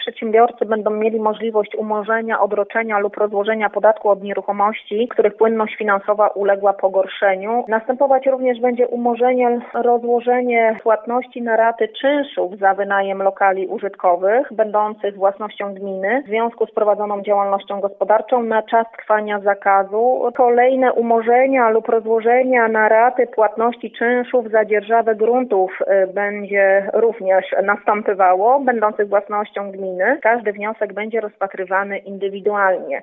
– Każda firma musi złożyć konkretny wniosek, który będzie indywidualnie rozpatrywany – powiedziała wójt Izabela Bojko: